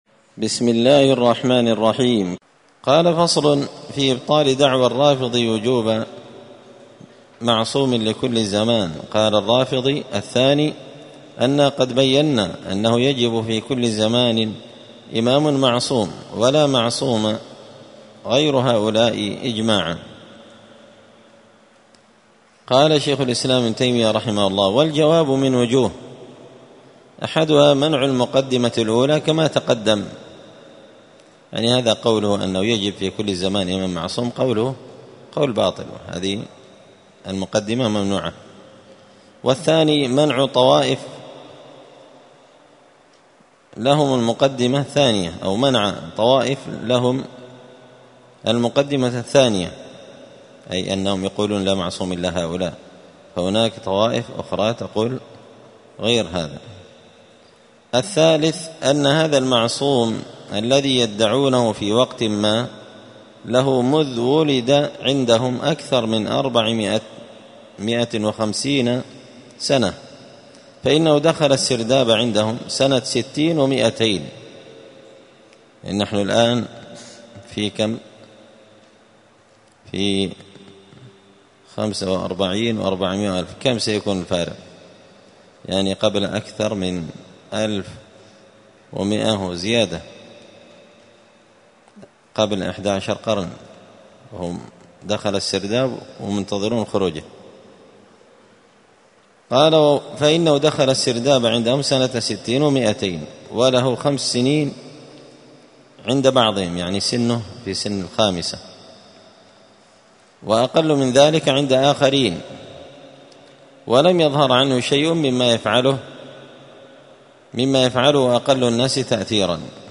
*الدرس الرابع والعشرون بعد المائتين (224) فصل في إبطال دعوى الرافضي معصوم لكل زمان واستدلاله بفضائل الإثني عشر على إمامتهم*
مسجد الفرقان قشن_المهرة_اليمن